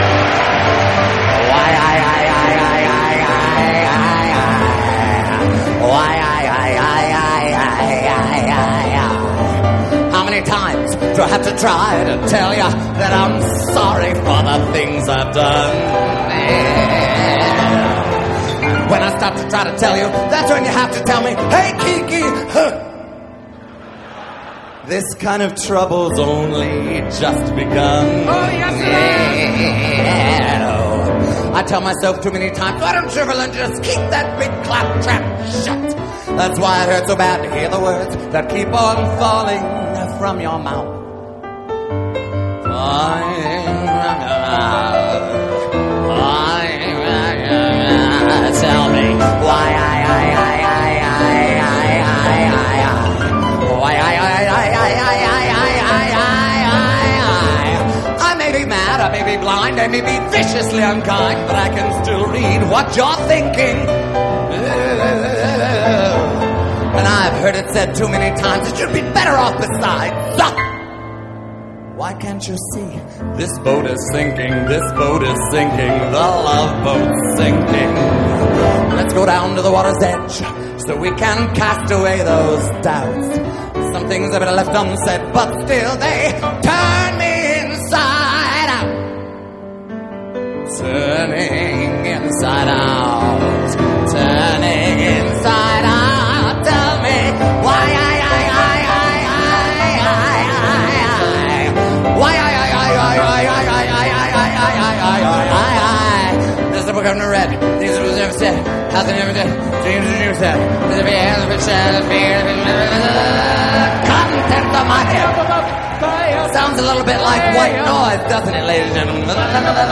Somehow, that cacophony calms me down.